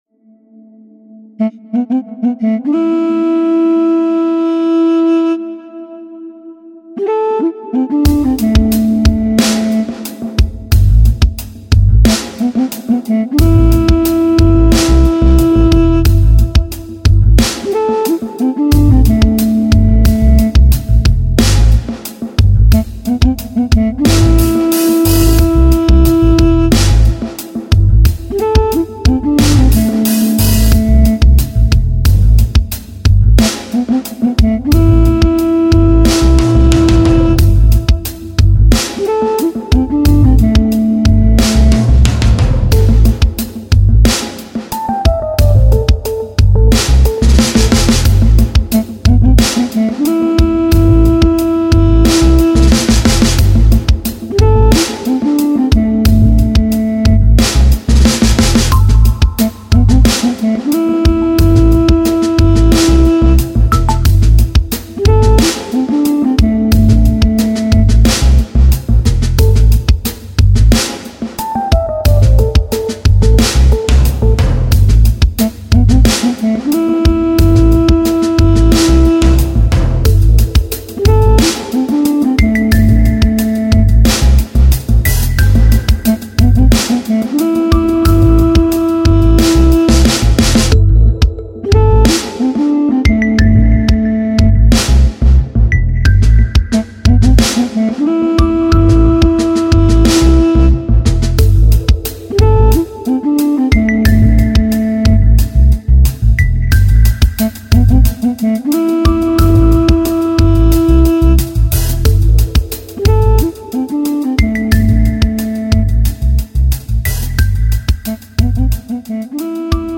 романтические